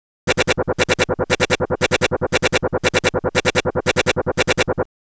The sound samples below are sequences consisting of two different types of sounds.
Sound B has been derived from sound A by varying only the brightness of A's timbre (using a low-pass filter).
However, try to focus on sound B. You will perceive a "BBBBB" continuous pattern.